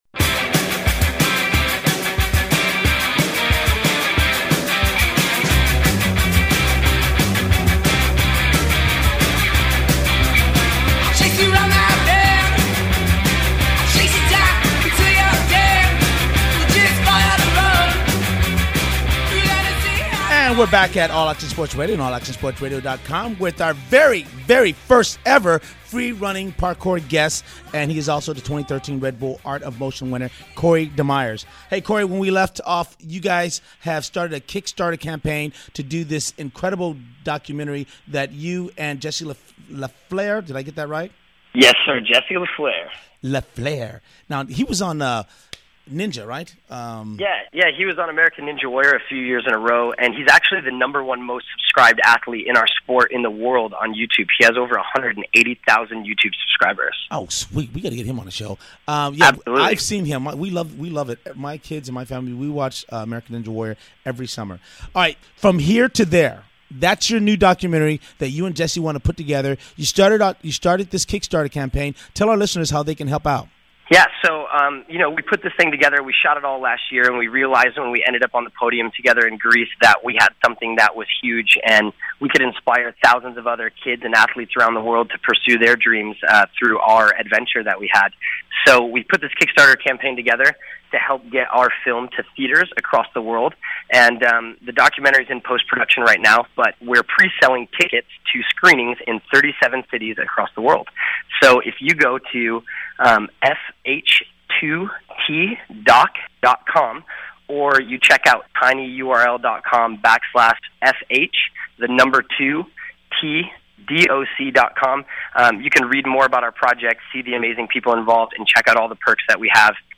AASR Athlete Interview